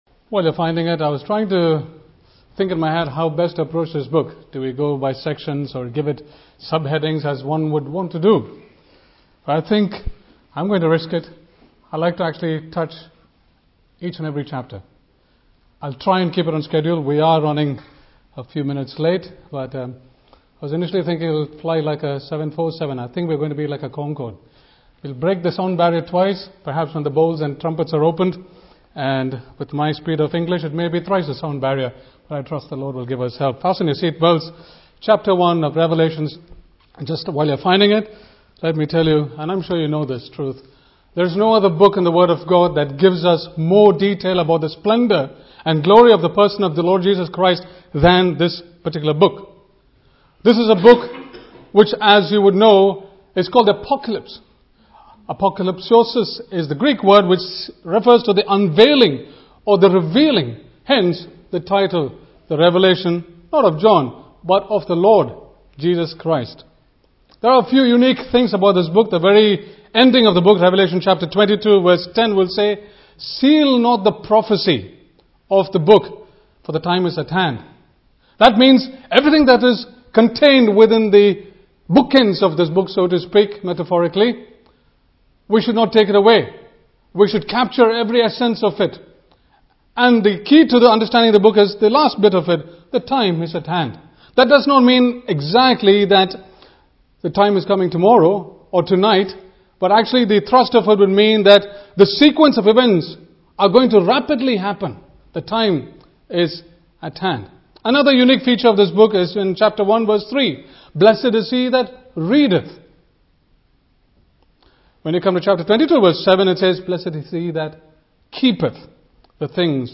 He outlines the unfolding of future events given in the book, which encompasses most of the details of the 7-year “tribulation” period of Bible prophecy including the 7 seals, 7 trumpets and 7 vials. The book is expounded within a pre-tribulational and pre-millennial framework with all the events from Ch 6:1 lying in the future, after the rapture of the church to heaven (Message preached 7th Apr 2016)